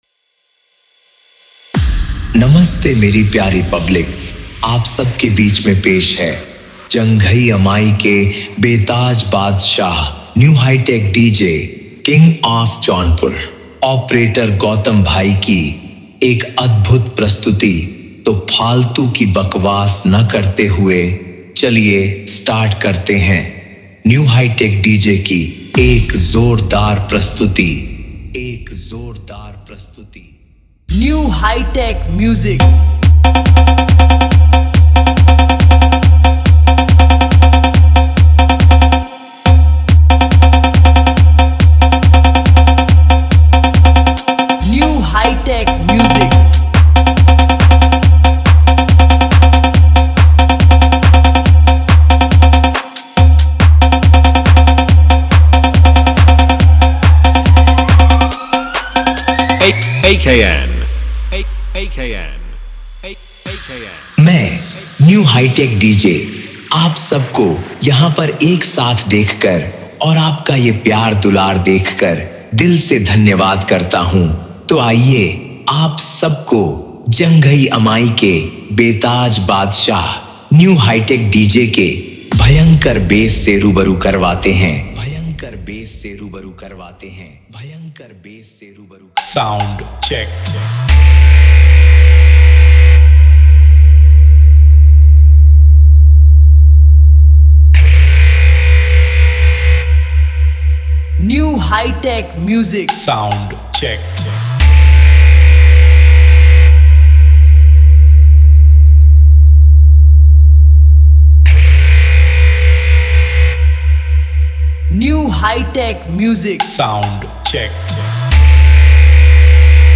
Competition DJ Beat, Intro Beat DJ Mix
Bass Boosted DJ Remix